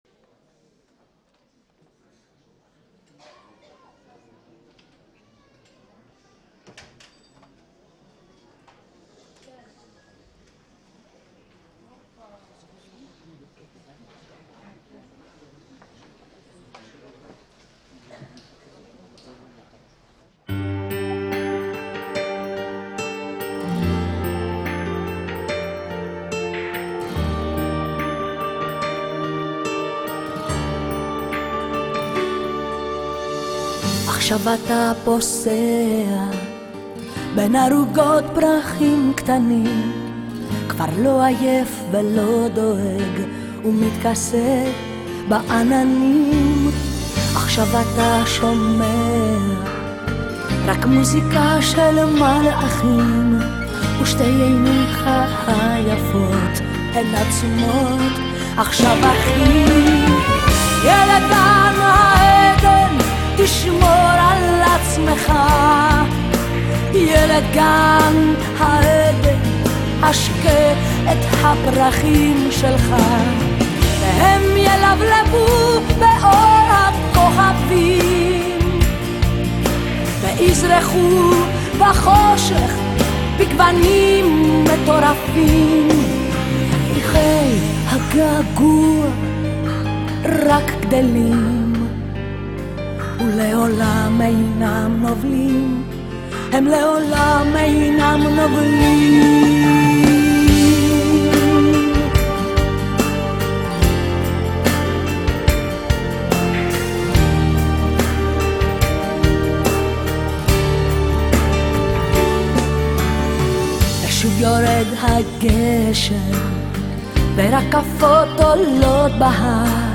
ילד גן עדן בפסטיבל להקות ראשונים, ירושלים 2010